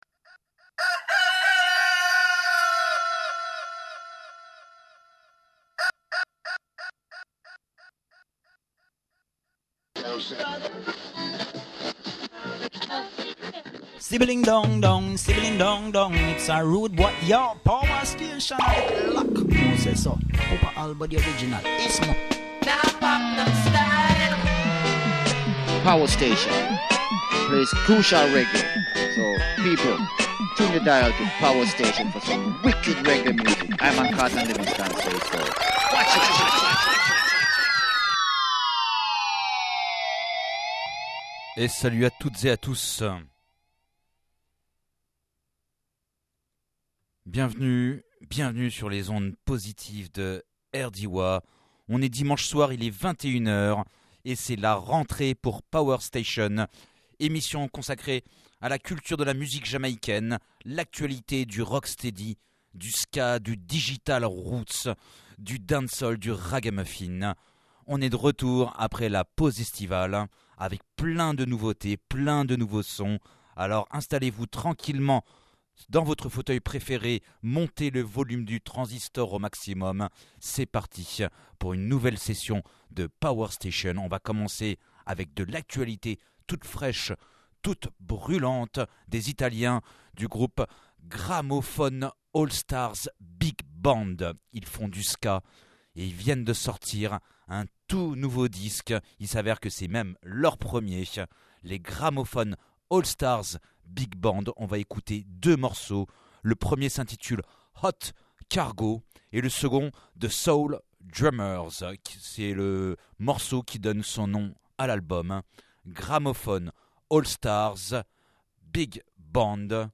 dub , reggae , ska